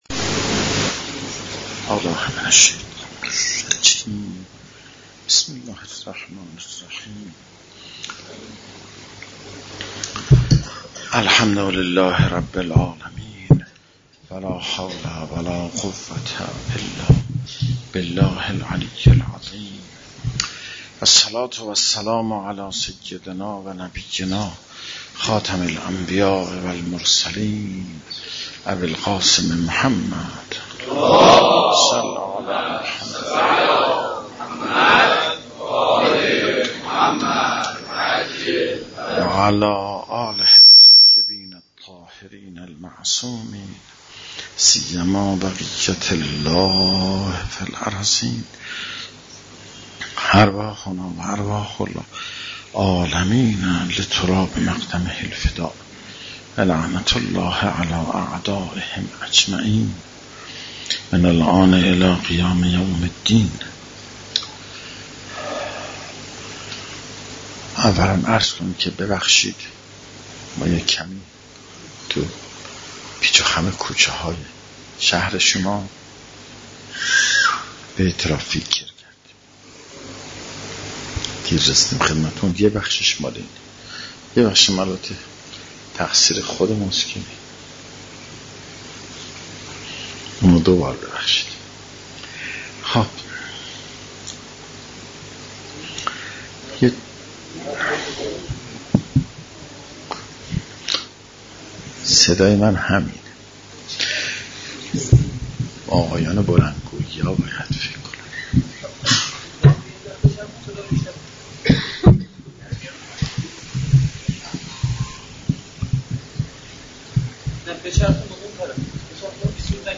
سخنرانی
در قرارگاه ولی امر مشهد